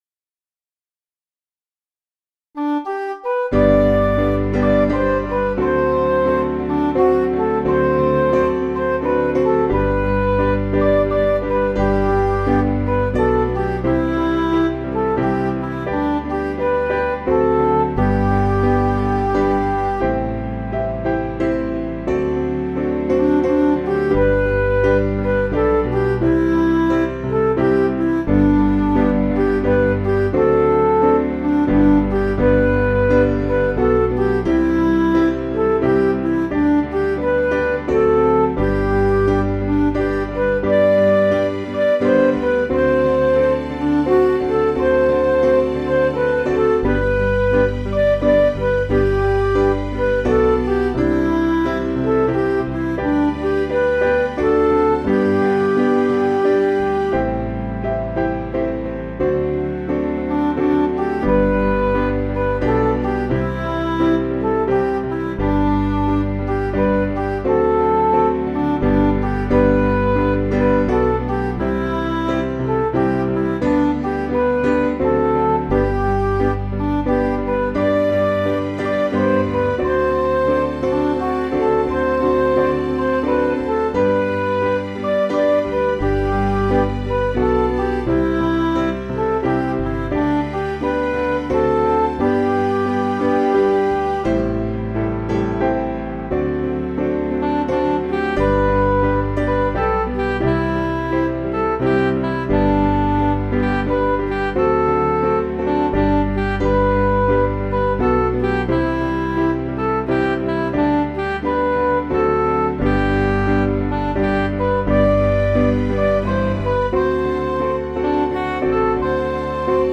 Piano & Instrumental
(slight swing)   493.6kb